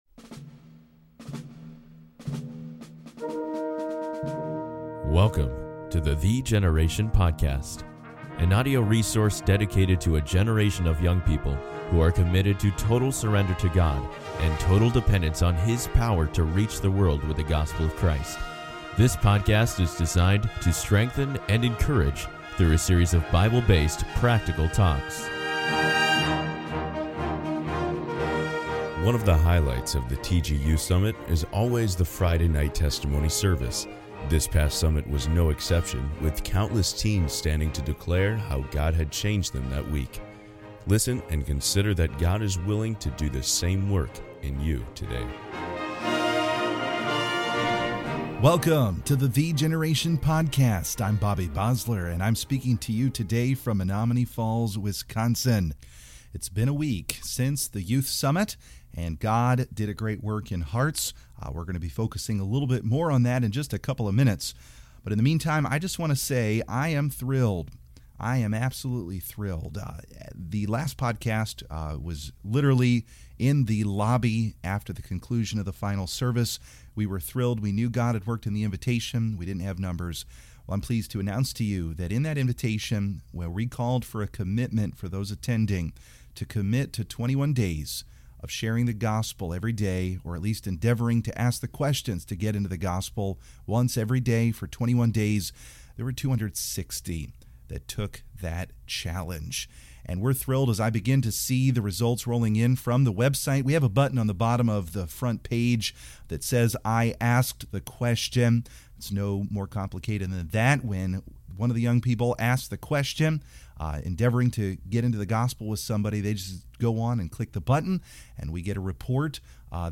One of the highlights of the TG Youth Summit is always the Friday night testimony service. This past Summit was no exception, with countless teens standing to declare how God had changed them that week.